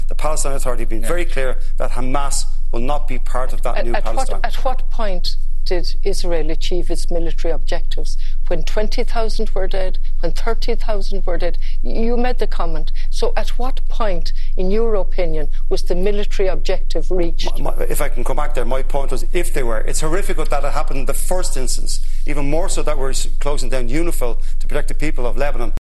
First Presidential debate of the campaign focused on Gaza
Connolly clashed with Gavin again over his comments that Israel had achieved its military objectives: